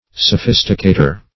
Sophisticator \So*phis"ti*ca`tor\, n.